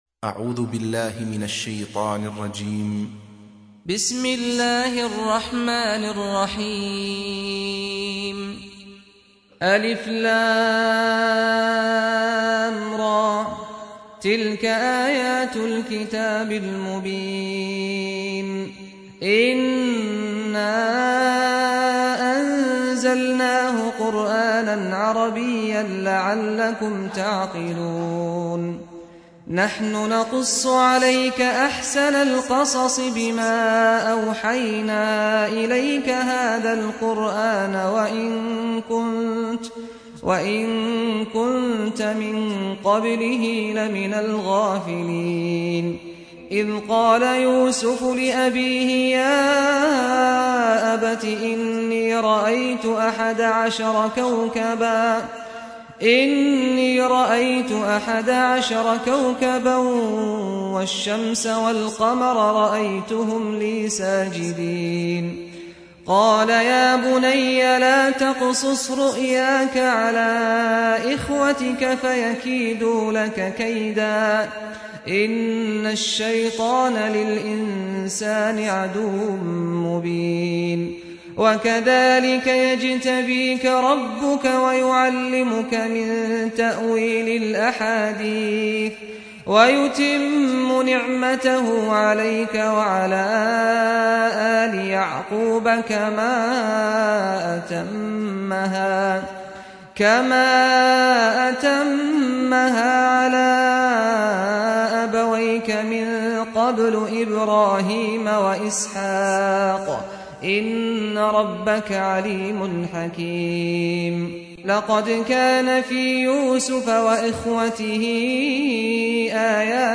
سُورَةُ يُوسُفَ بصوت الشيخ سعد الغامدي